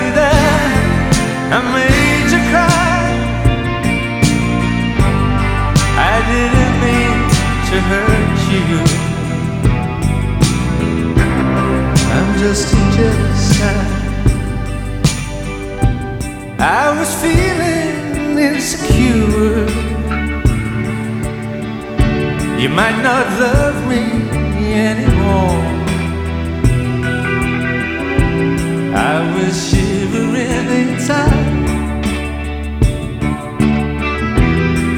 Жанр: Поп / Рок / Альтернатива / Панк